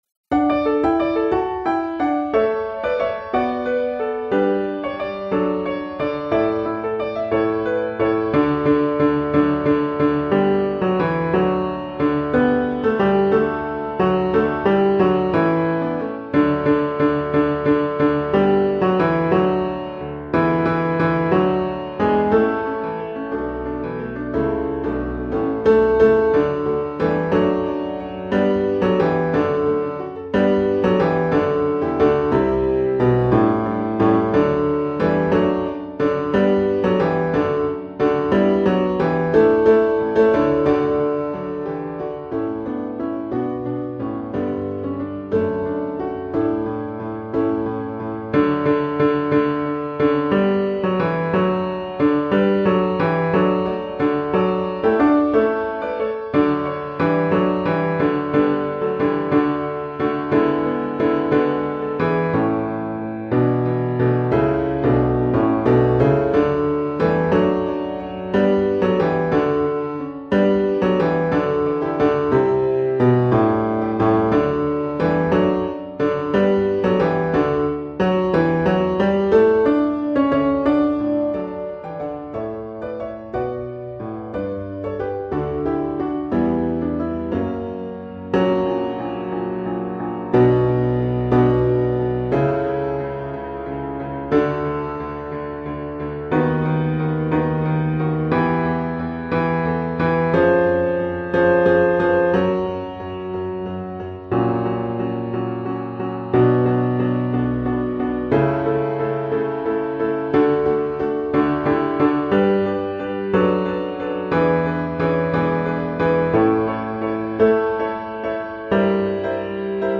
Joy Overflowing – Bass